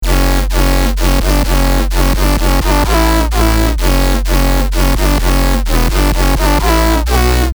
❇ Ready for BASS HOUSE, TRAP, UKG, DRUM & BASS, DUBSTEP and MORE!
WUB - Bass House 1 [F#min] 128BPM
WUB-Bass-House-1-Fmin-128BPM.mp3